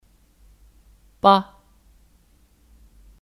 吧 (Ba 吧)